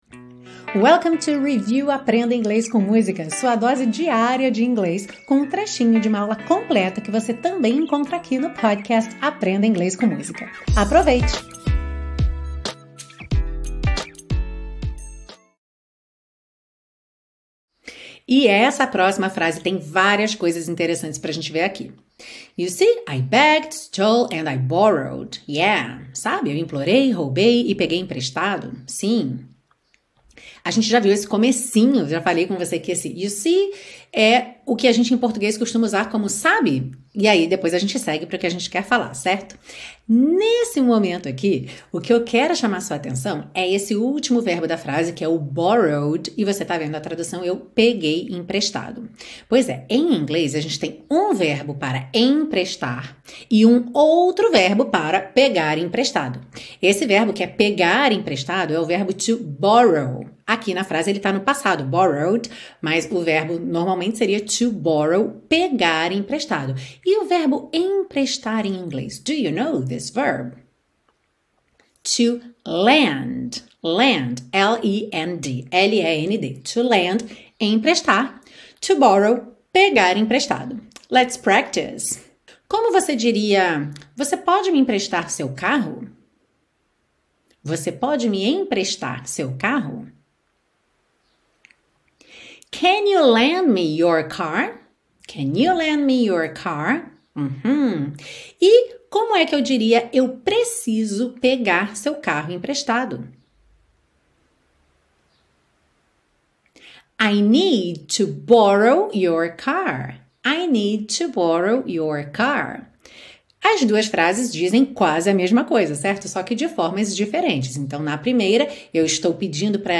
Este é só um trecho da aula completa da música "Easy" com "The Commodores", que você encontra aqui no podcast "Aprenda Inglês com Música".